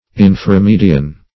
Search Result for " inframedian" : The Collaborative International Dictionary of English v.0.48: Inframedian \In`fra*me"di*an\, a. [Infra + median.]